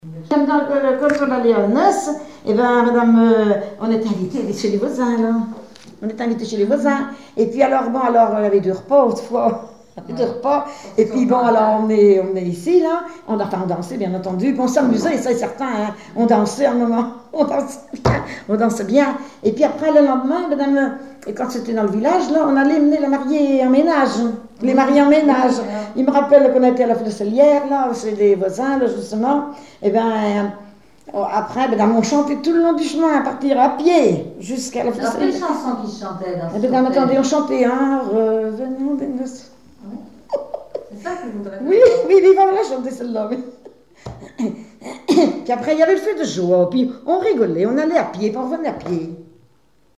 Chansons et témoignages
Catégorie Témoignage